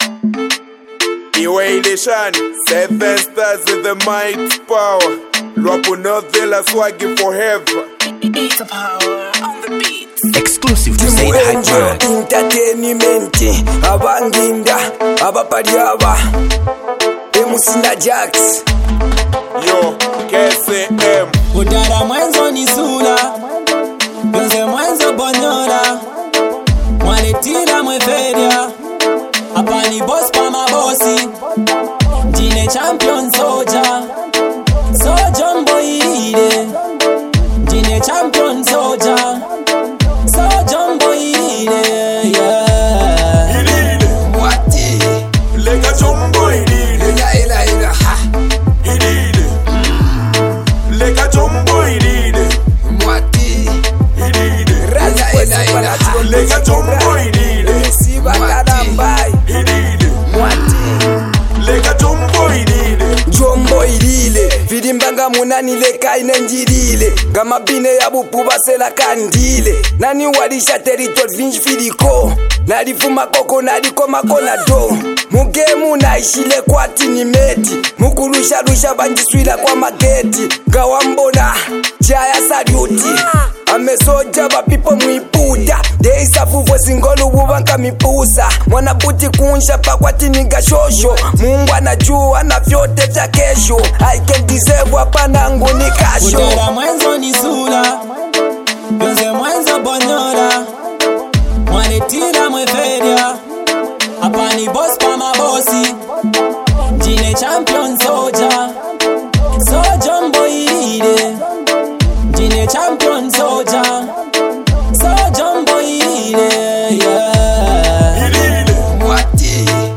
HipHop duo
The rap fused club banger